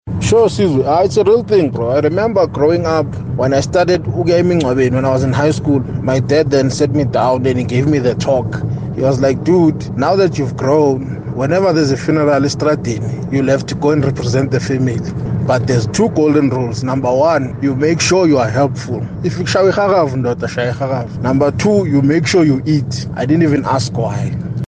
Kaya Drive listeners share their views on snubbing food at funerals: